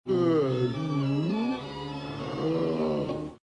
Play, download and share Bostezo original sound button!!!!
bostezo.mp3